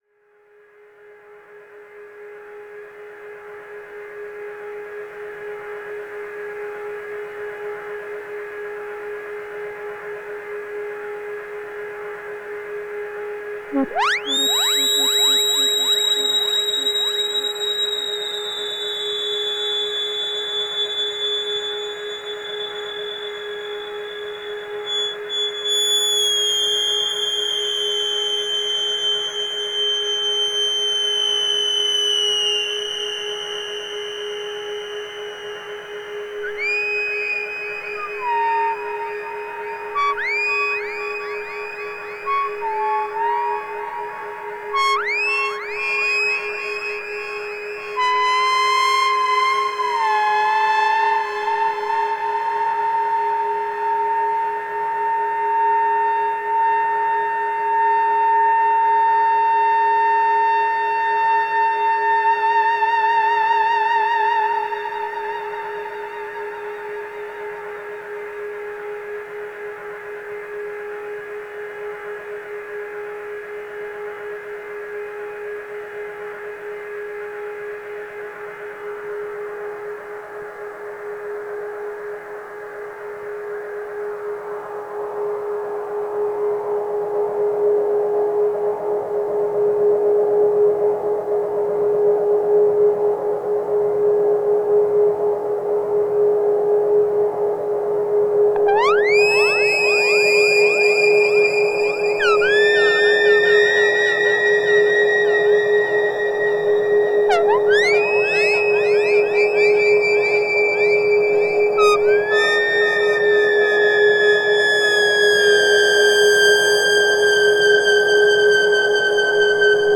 Exploration du A100 + Model-D au ruban